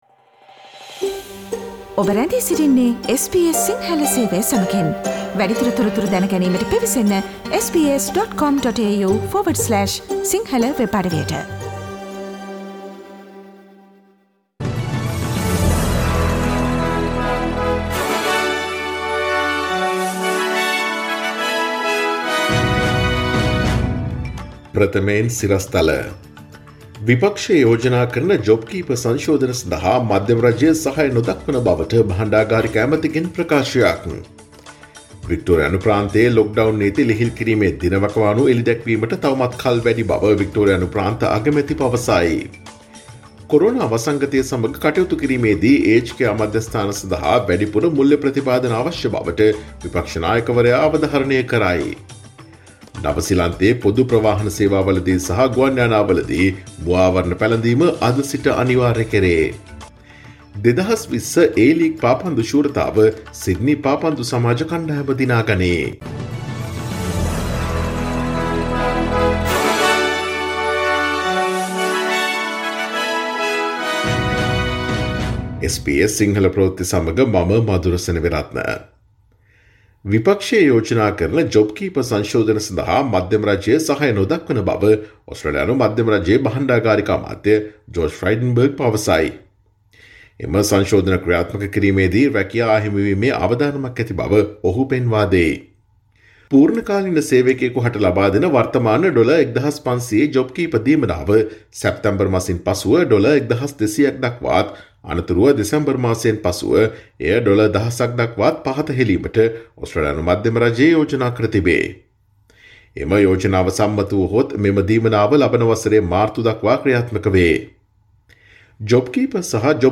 Daily News bulletin of SBS Sinhala Service: Monday 31 August 2020
Today’s news bulletin of SBS Sinhala Radio – Monday 31 August 2020 Listen to SBS Sinhala Radio on Monday, Tuesday, Thursday and Friday between 11 am to 12 noon